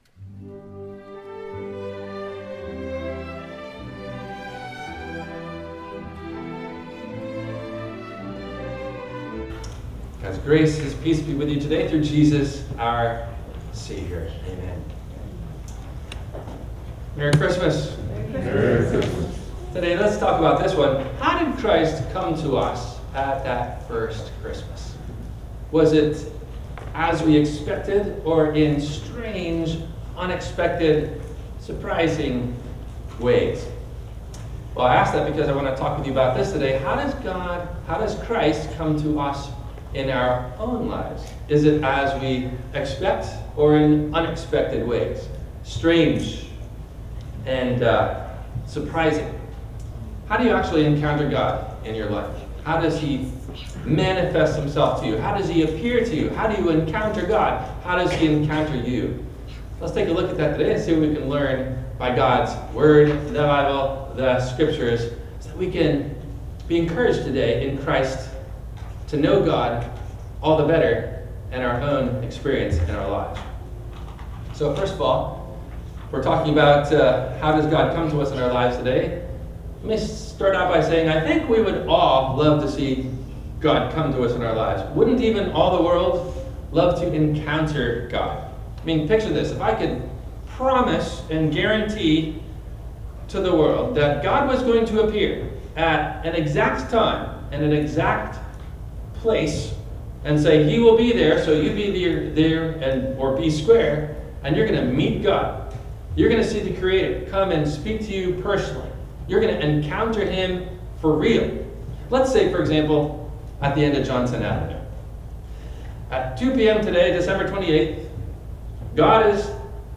How Does God Come To Us – In Expected or Unexpected Ways? – WMIE Radio Sermon – January 05 2025
No Questions asked before the Message.